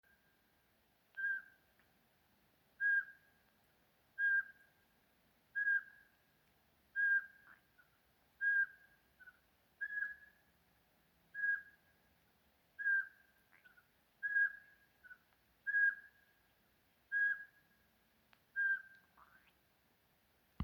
Putni -> Pūces ->
Apodziņš, Glaucidium passerinum